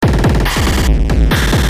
Tag: 140 bpm Glitch Loops Drum Loops 295.55 KB wav Key : Unknown